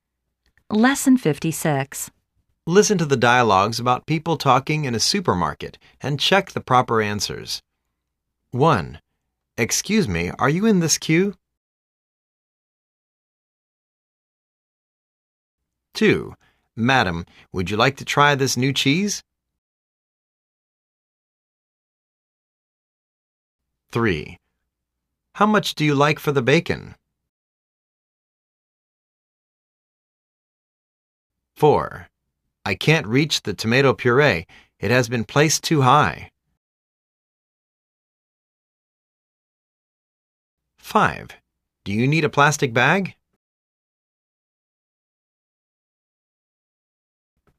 Listen to the dialogues about people talking in a supermarket and check the proper answers.